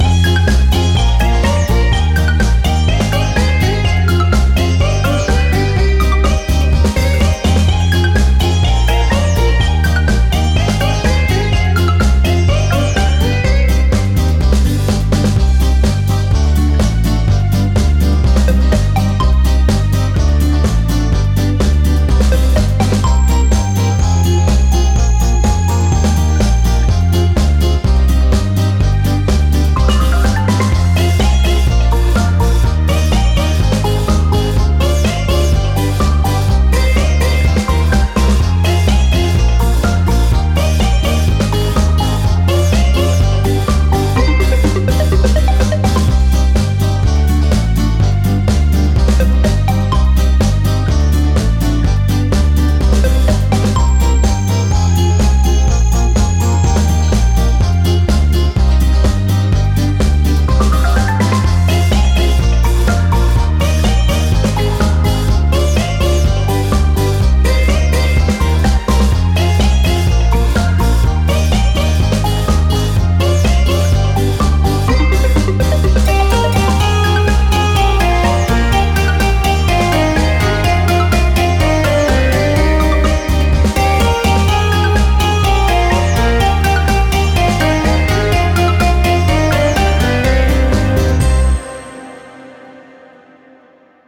• Категория: Детские песни
караоке
минусовка